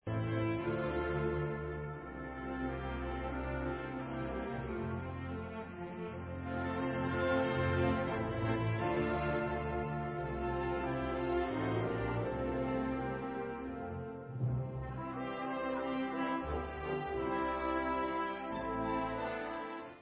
As dur (Poco allegro) /Polka